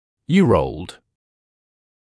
[ˌjɪə’rəuld][ˌйиэ’роулд]-летний (о возрасте)